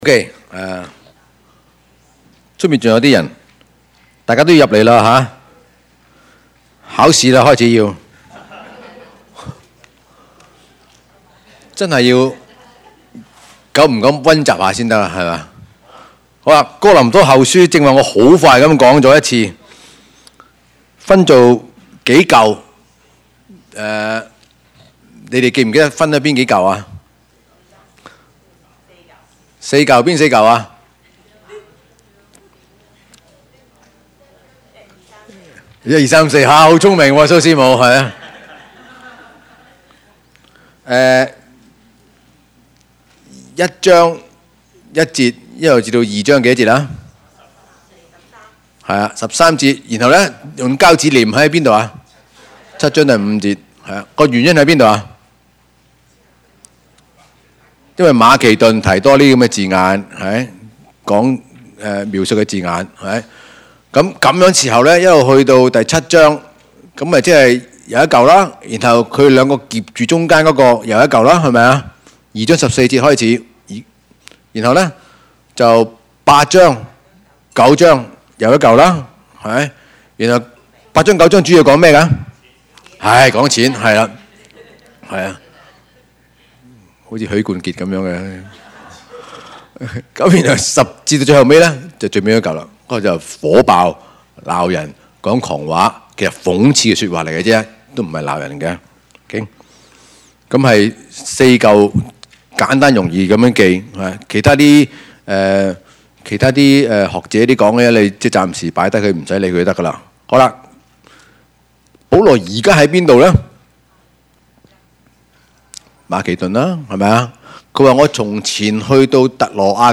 Passage: 哥林多後書 七：5-16 Service Type: 主日崇拜